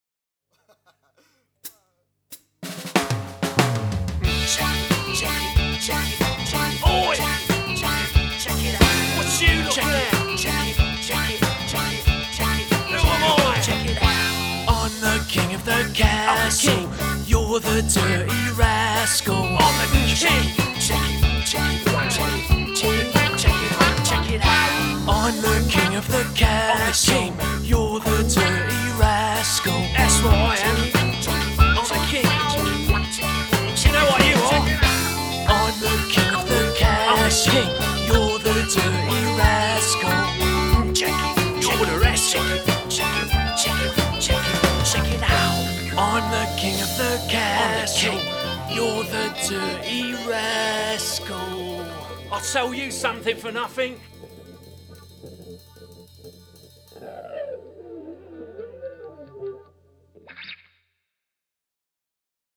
Fun & Punk